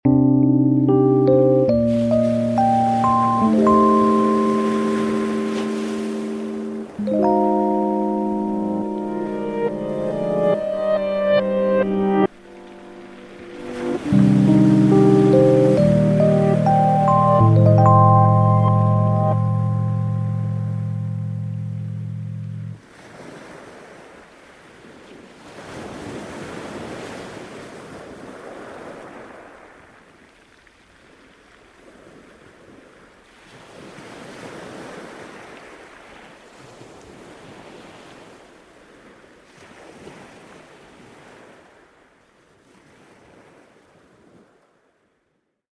Texturas orquestales y ambientales electrónicas